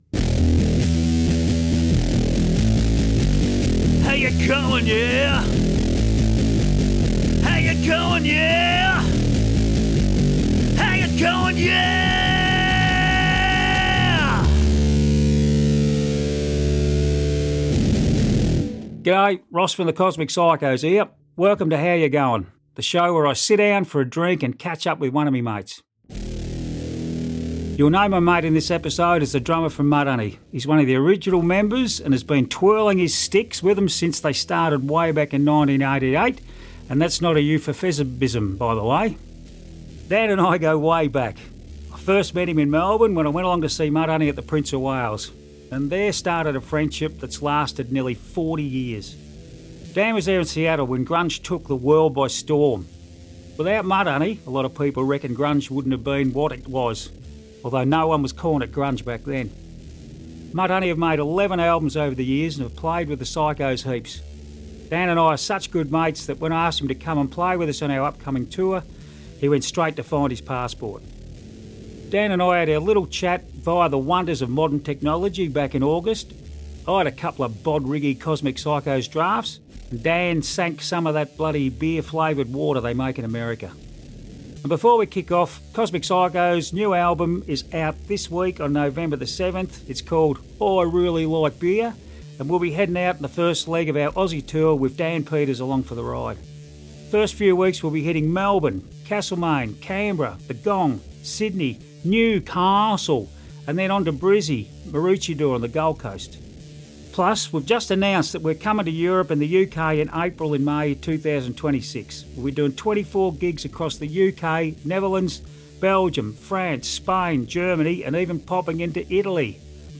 We had our little chat via the wonders of modern technology back in August.